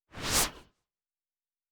pgs/Assets/Audio/Sci-Fi Sounds/Movement/Synth Whoosh 4_3.wav at master
Synth Whoosh 4_3.wav